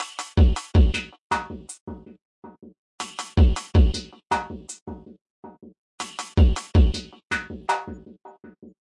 电子音乐循环
描述：电子音乐循环
标签： 电音 原版 音乐 节拍 techno 电子 舞蹈 循环 合成器 bass house
声道立体声